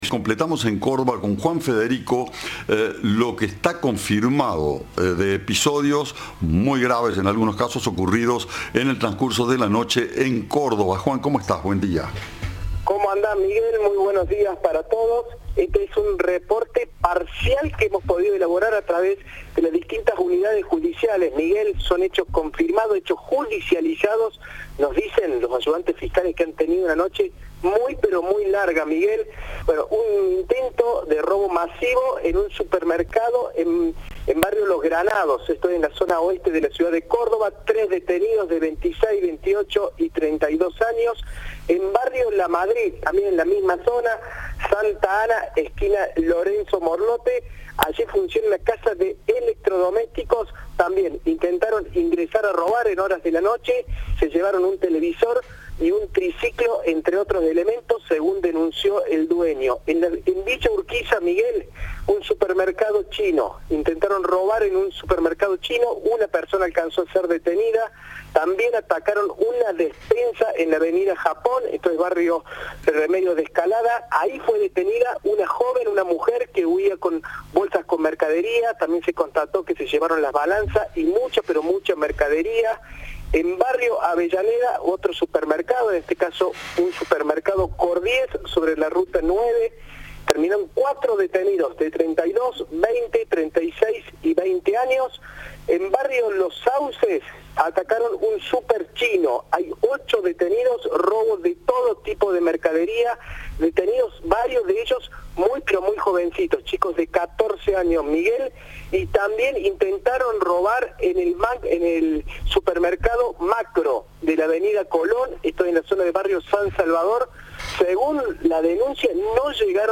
Lo dijo a Cadena 3 el fiscal que investiga los hechos cometidos en la madrugada del martes, Ernesto de Aragón.
El fiscal Ernesto de Aragón informó este martes a la noche, en diálogo con Cadena 3, que todos los detenidos en la madrugada continúan demorados.